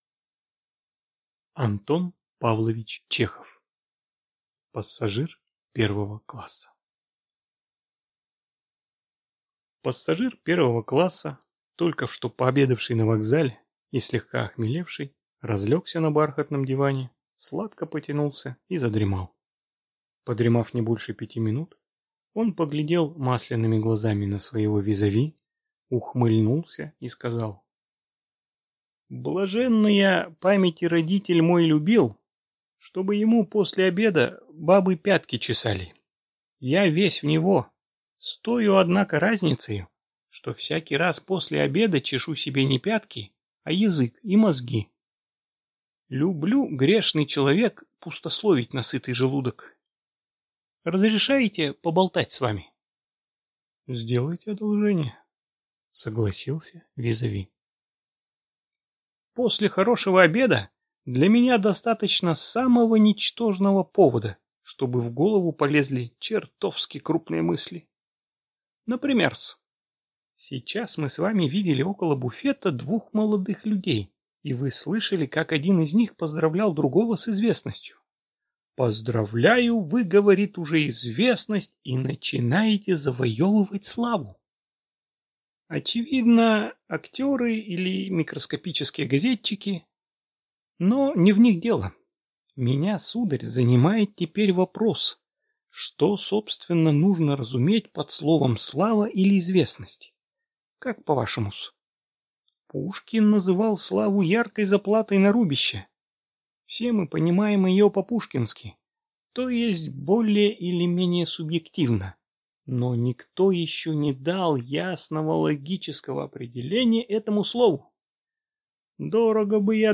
Аудиокнига Пассажир 1-го класса